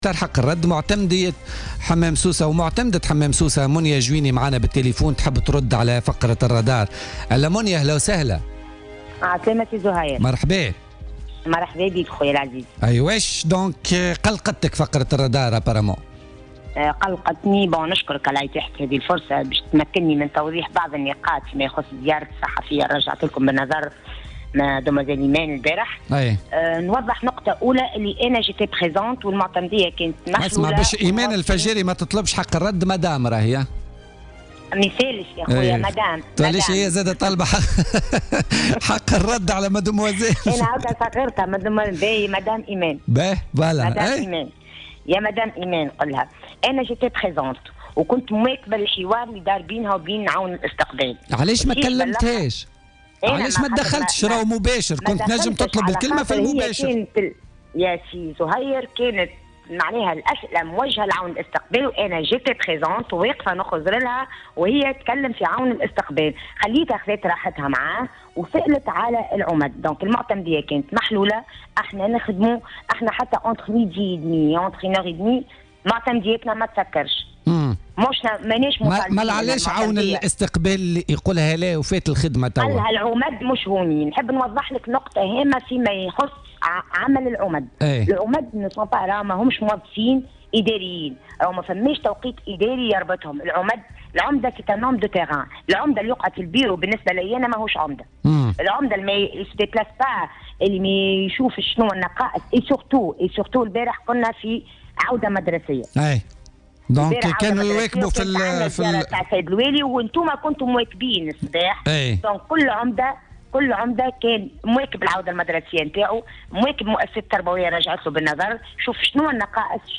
أكدت معتمدة حمام سوسة منية الجويني التي طلبت حق الرد في برنامج بوليتيكا على...